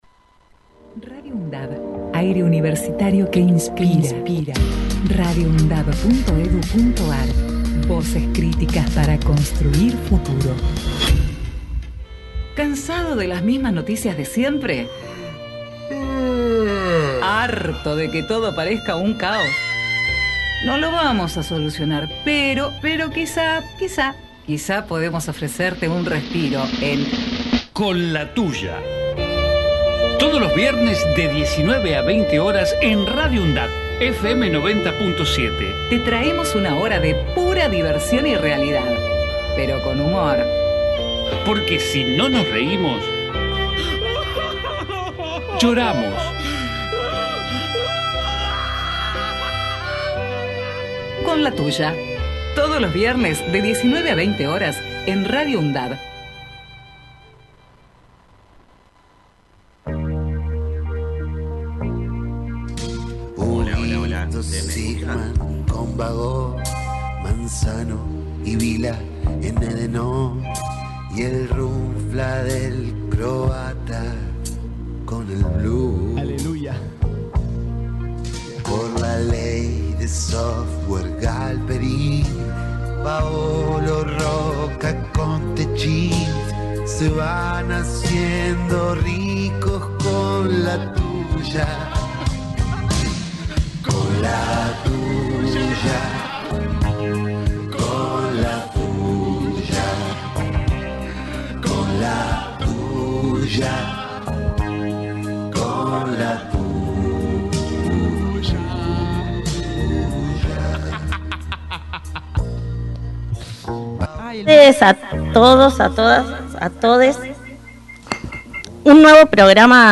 Cuenta también con una columna itinerante, con entrevistas e invitados especiales que serán parte de este programa que sale los viernes de 19 a 20. Con la tuya por Radio UNDAV Archivo de audio: CON LA TUYA 2025-07-18.mp3 Programa: Con la tuya